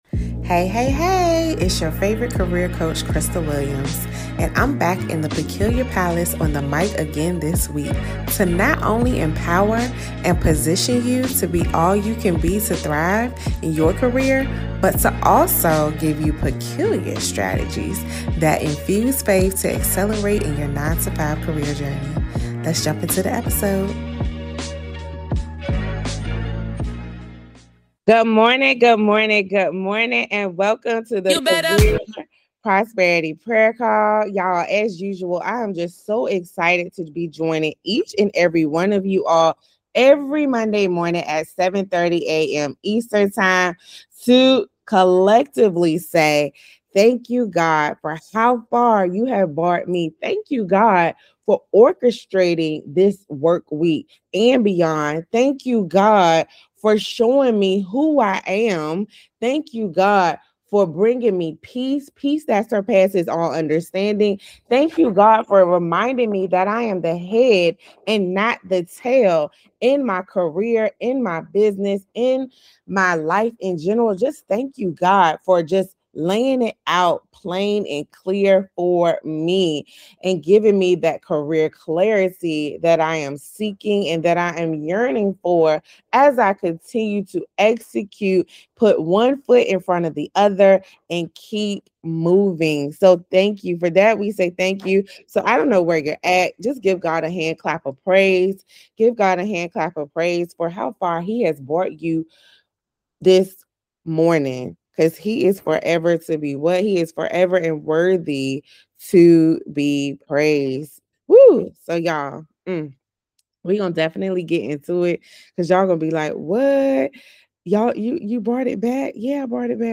In this live session, we are breaking down why you need to stop begging for opportunities and start recognizing the immense value, skills, and God-given gifts you bring to the marketplace.